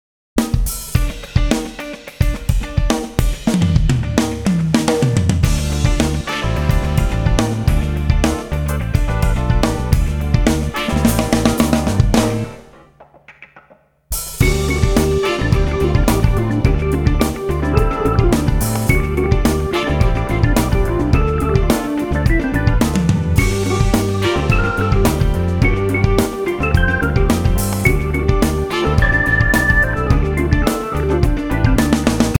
107 BPM